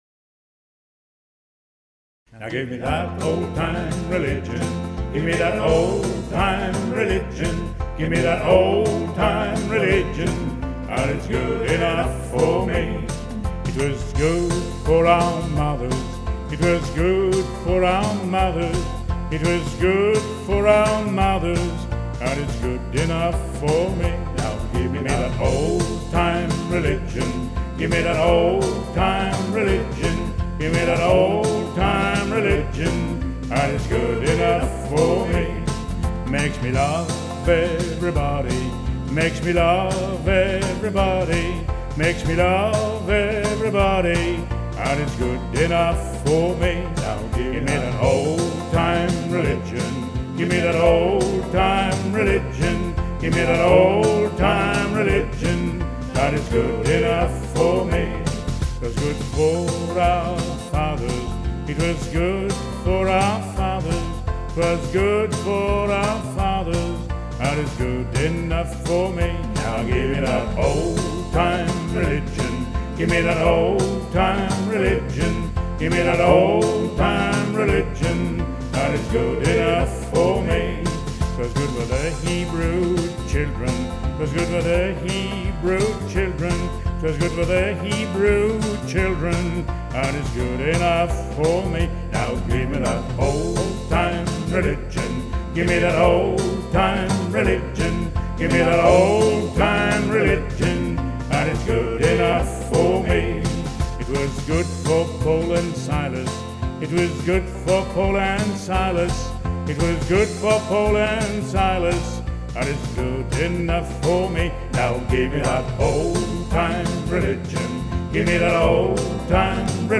Une chanson